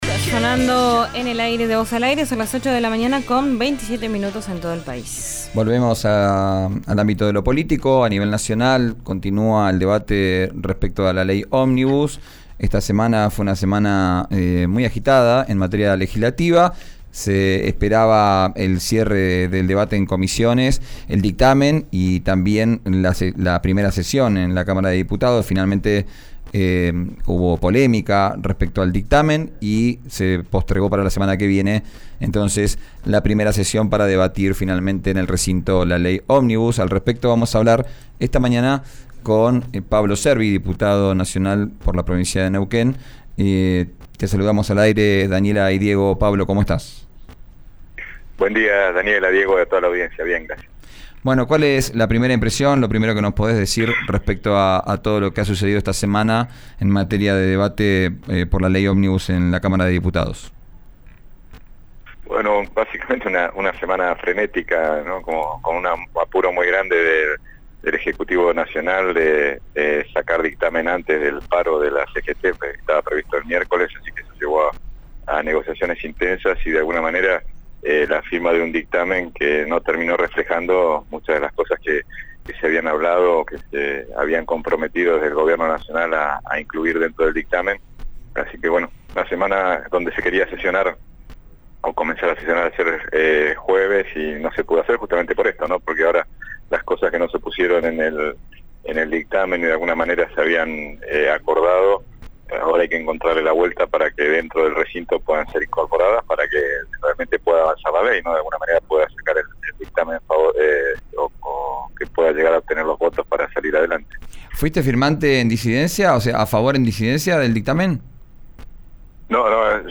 El diputado nacional de Neuquén por la UCR cuestionó el 'apriete' a gobernadores y legisladores. En RÍO NEGRO RADIO, contó que amenazaron y trataron de 'coimeros' a quienes no apoyan la iniciativa.